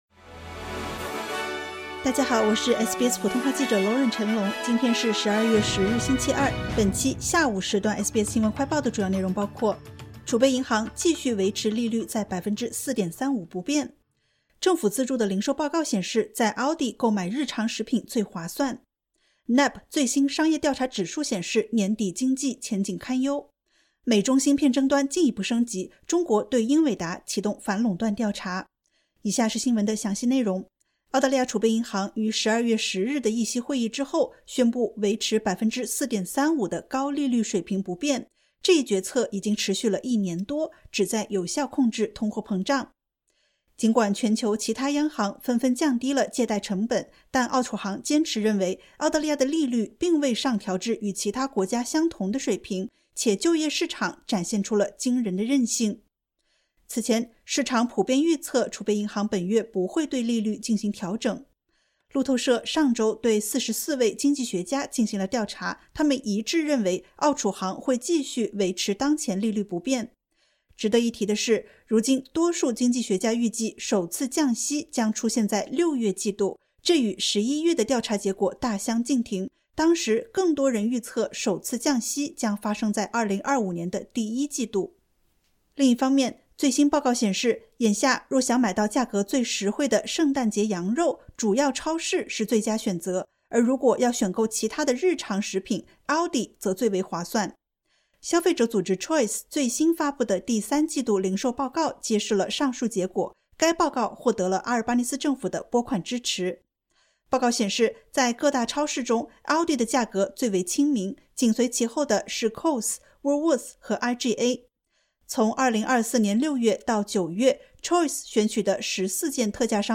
【SBS新闻快报】储备银行继续维持利率4.35%不变